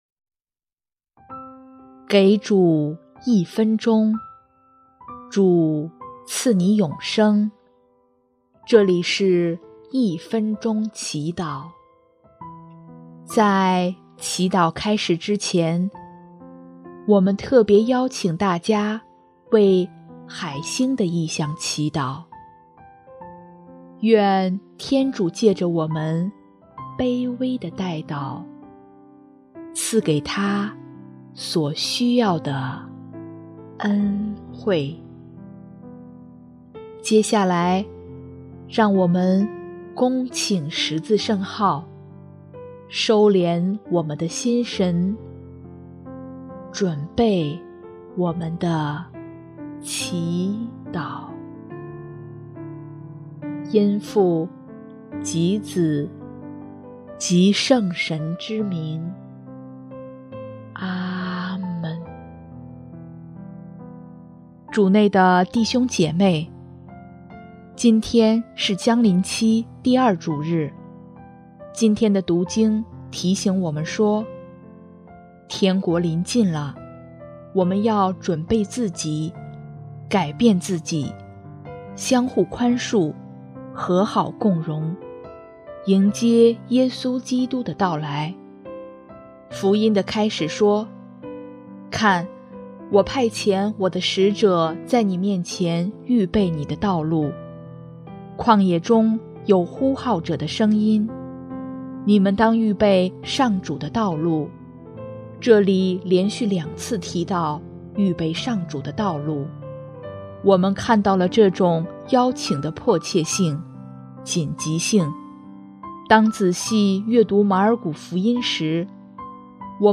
音乐：主日赞歌《预备主道路》